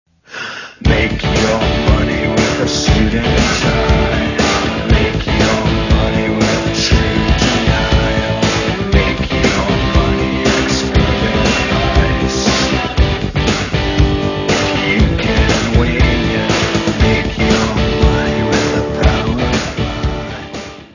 : stereo; 12 cm + folheto
Área:  Pop / Rock